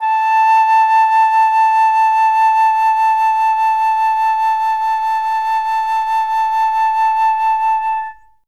51c-flt08-A4.wav